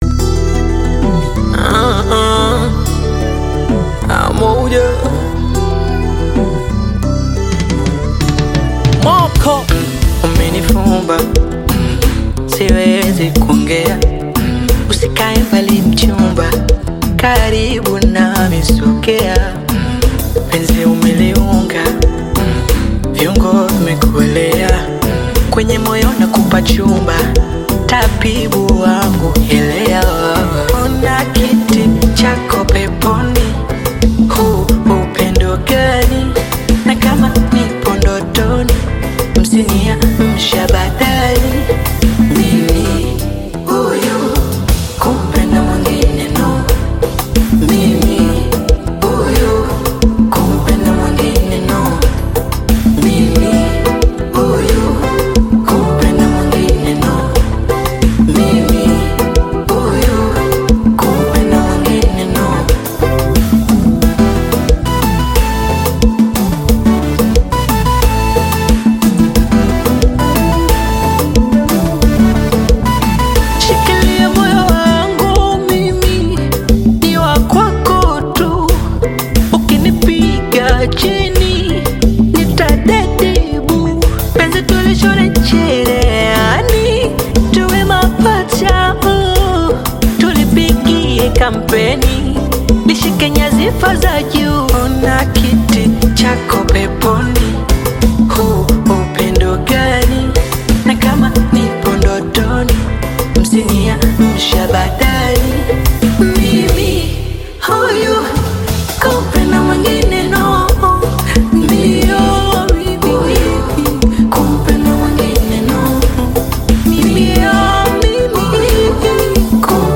Tanzanian Bongo Flava artist